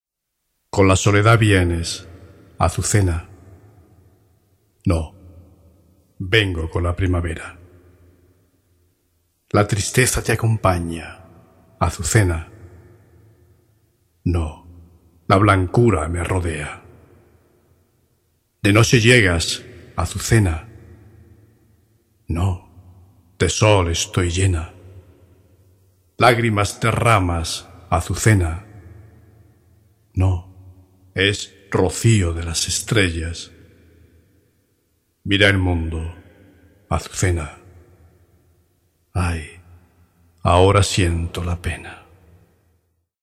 Poesía
el Ney (flauta de caña).